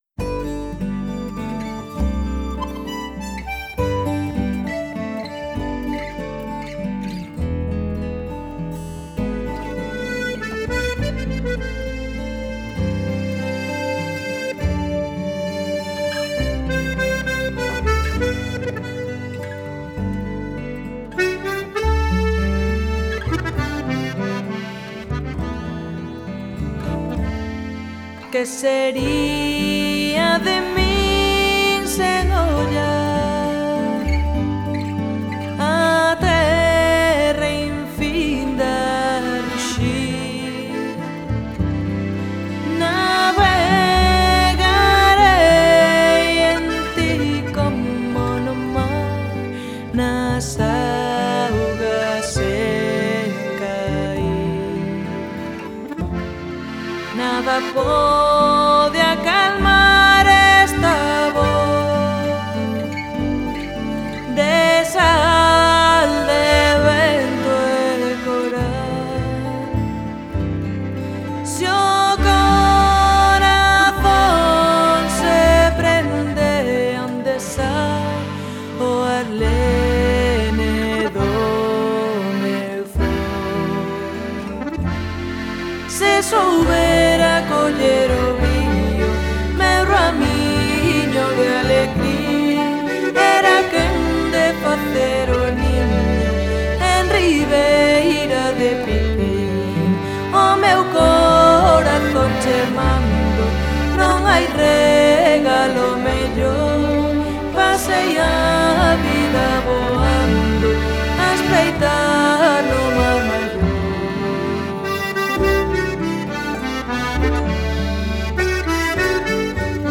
Folk / Tradicional / World music